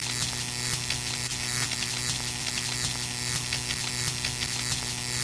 Погрузитесь в атмосферу ночного города с подборкой звуков неона: мерцание вывесок, тихий гул ламп, электронные переливы.
Шум от неоновой подсветки звук